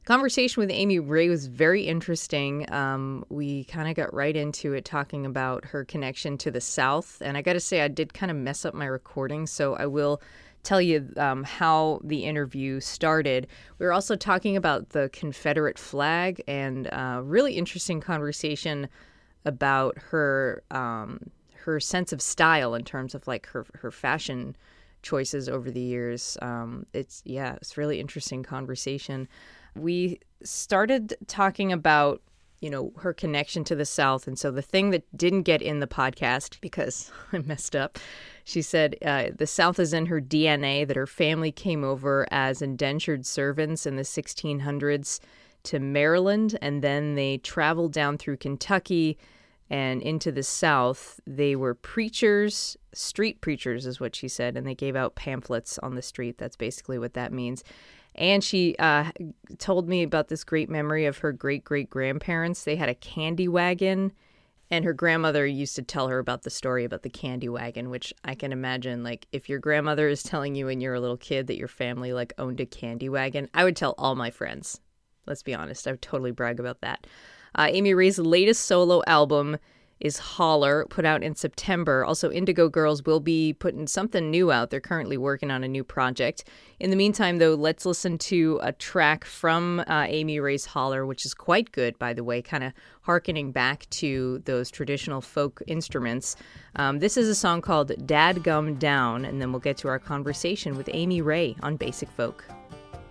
(recorded from webcast)
02. interview (amy ray) (1:43)